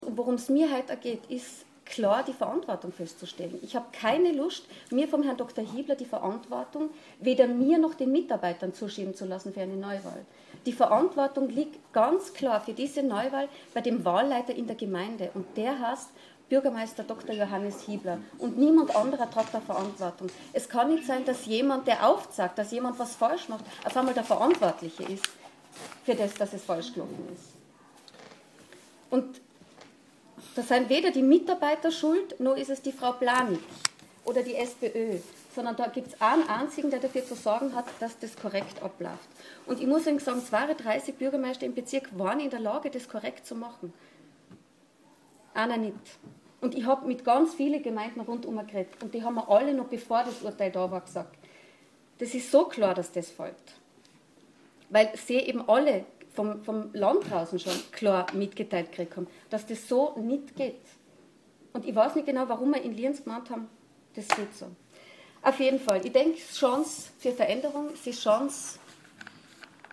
Zu Blaniks Sicht von der Verantwortung für die Wahlwiederholung haben wir den O-Ton der Pressekonferenz für Sie.